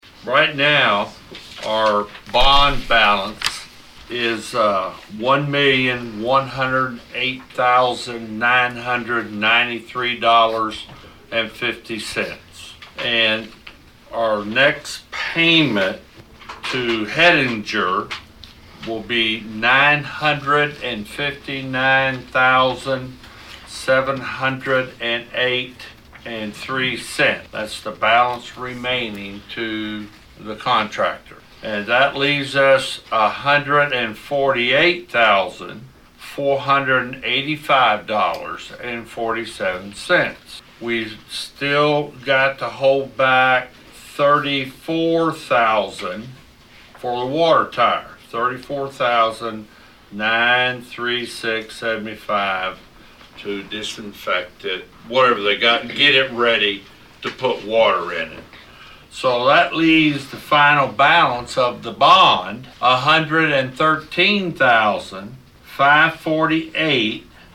During the meeting of the Develop Saline County Advisory Board on Wednesday, April 14, Presiding Commissioner Kile Guthrey Jr. gave an update on the bond for the waterline project at Marshall Junction.